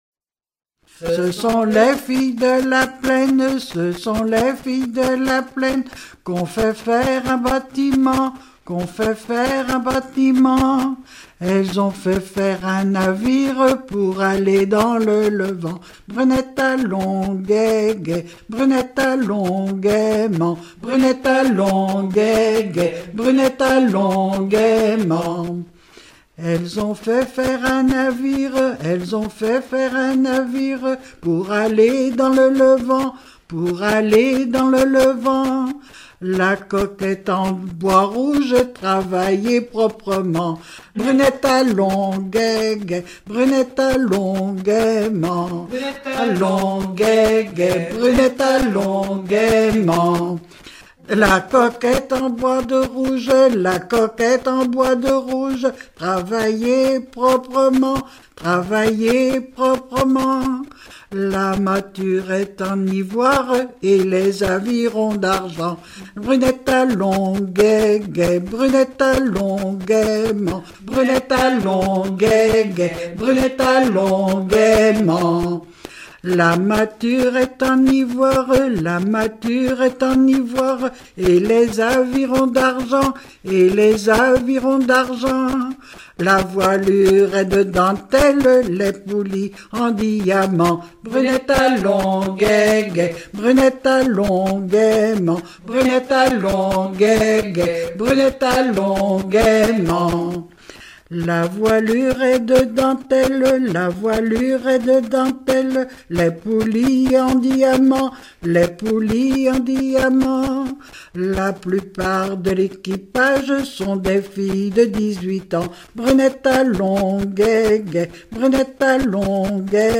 Nalliers ( Plus d'informations sur Wikipedia ) Vendée
Genre laisse